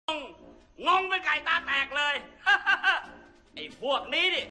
Genre: เอฟเฟ็กต์เสียง